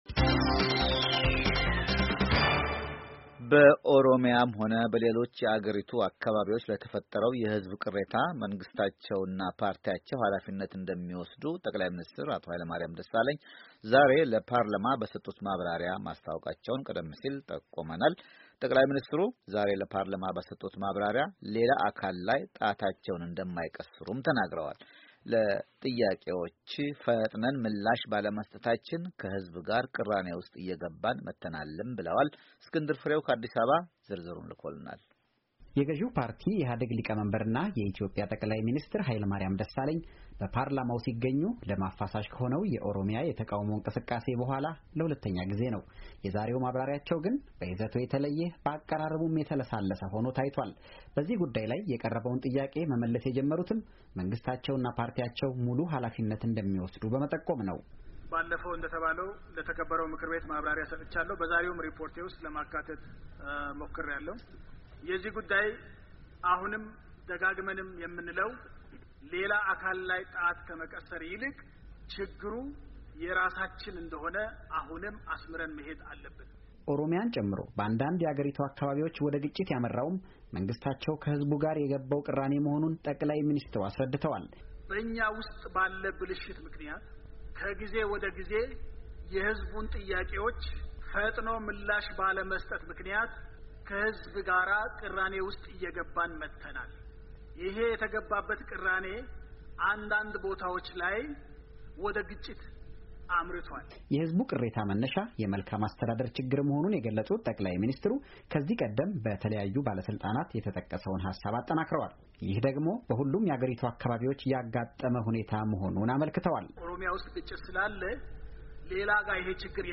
ከአዲስ አበባ ተከታዩን ዘገባ ልኳል።